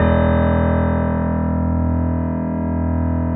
Index of /90_sSampleCDs/USB Soundscan vol.17 - Keyboards Acoustic [AKAI] 1CD/Partition B/02-GRANDP MO